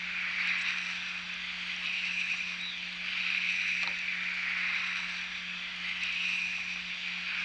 A study evaluating the potential of using acoustic monitoring for remote assessment of flying animal collisions at industrial wind energy facilities
Presumed flying animal collision sound - September 29, 2008 - #2